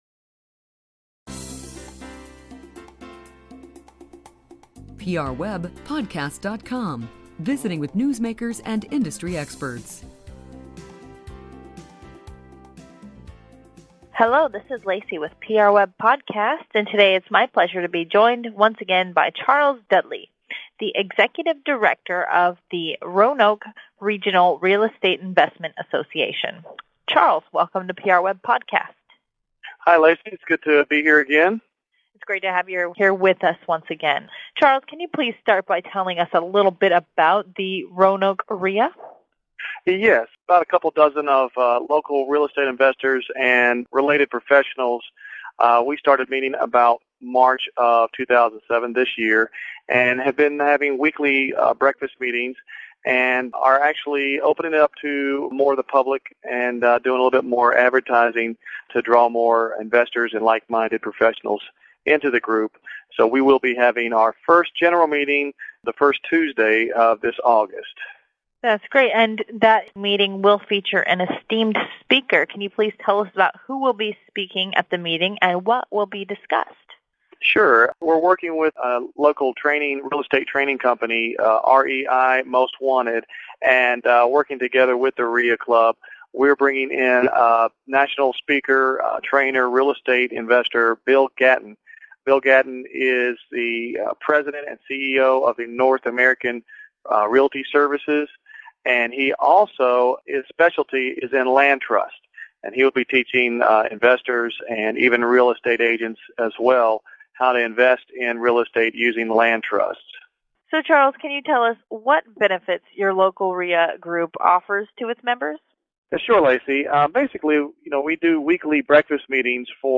Every day we select and conduct brief interviews around the top press releases at PRWeb.